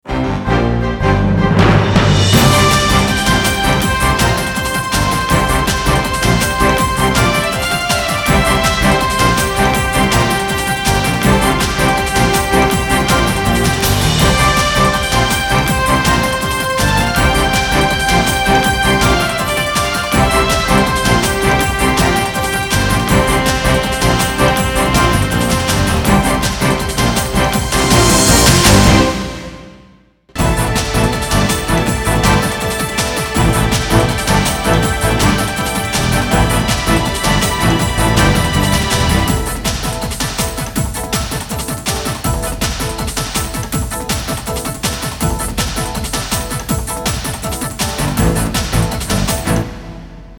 电视包装音乐